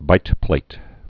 (bītplāt)